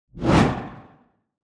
دانلود آهنگ باد 37 از افکت صوتی طبیعت و محیط
جلوه های صوتی
دانلود صدای باد 37 از ساعد نیوز با لینک مستقیم و کیفیت بالا